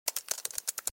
typing.mp3